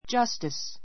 justice dʒʌ́stis